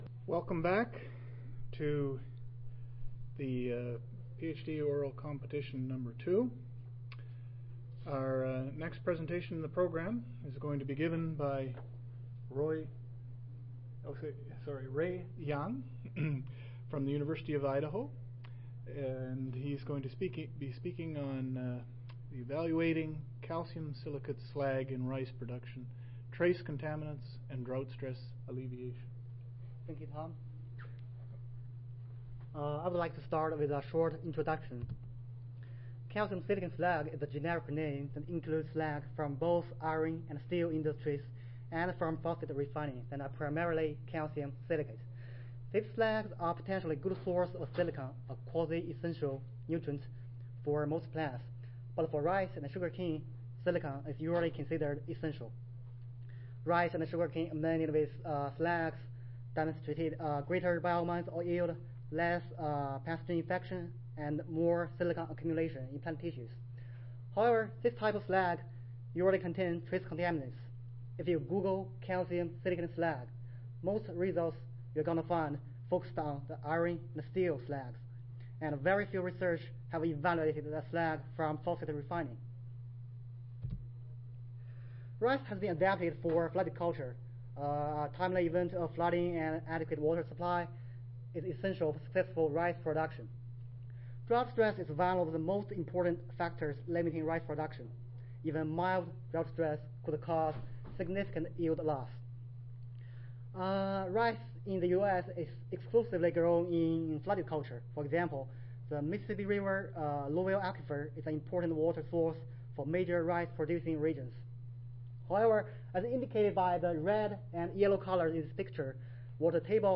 See more from this Division: SSSA Division: Soil Fertility and Plant Nutrition See more from this Session: Ph.D. Oral Competition II